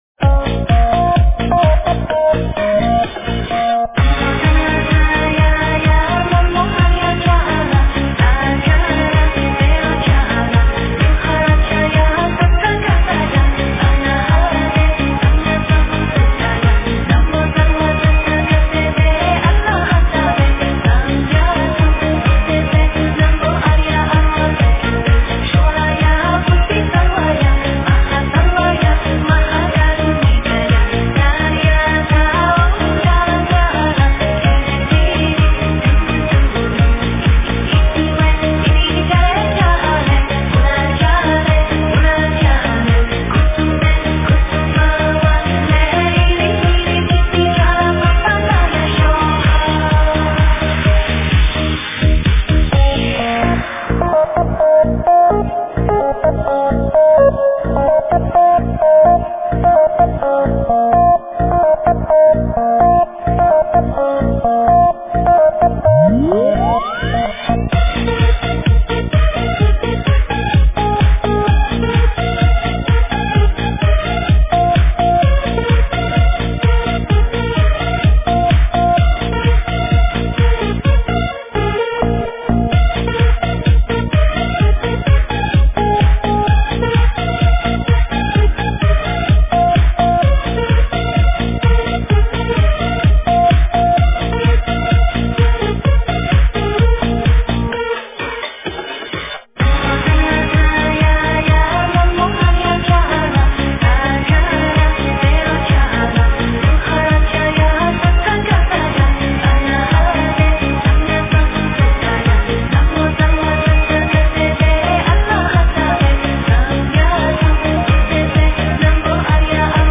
女子DJ版
佛教音乐